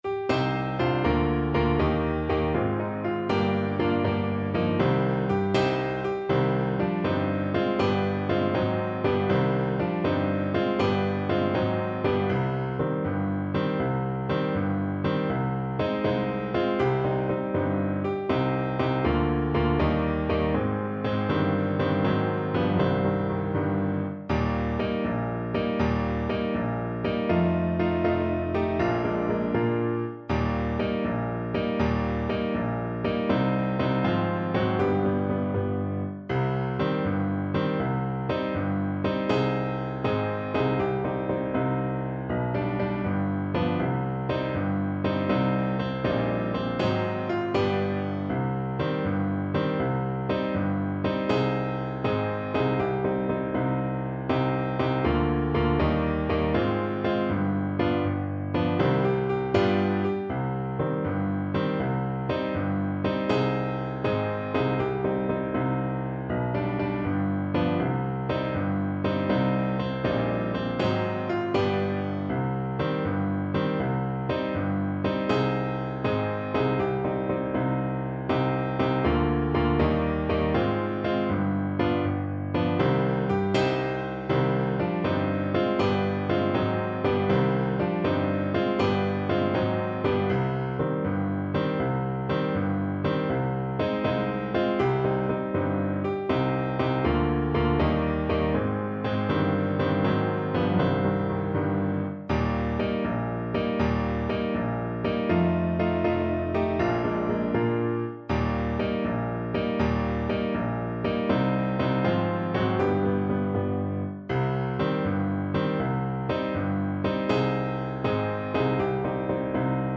6/8 (View more 6/8 Music)
Brightly, but not too fast